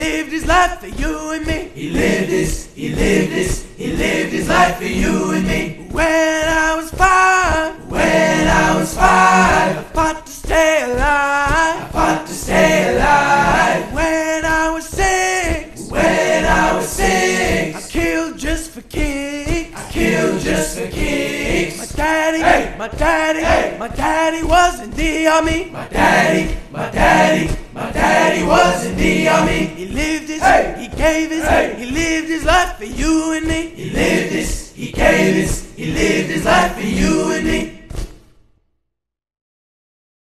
🔫 Here’s the Army cadence